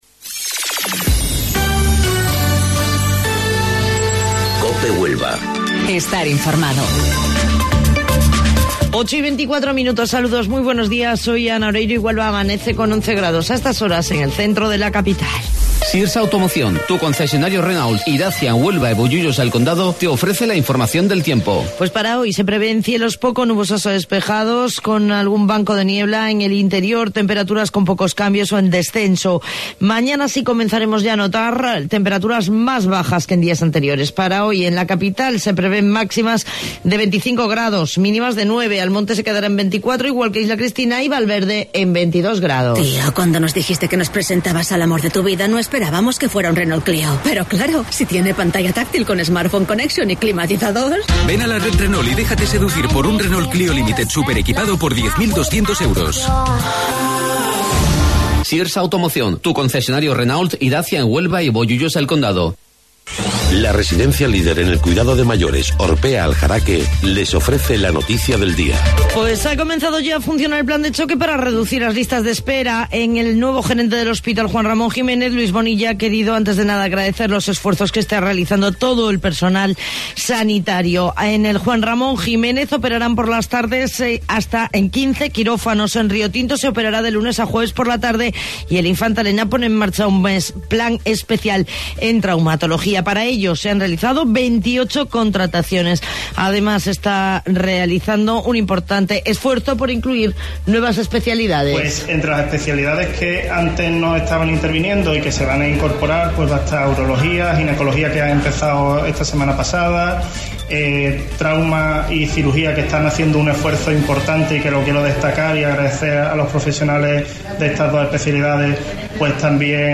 AUDIO: Informativo Local 08:25 del 3 de Abril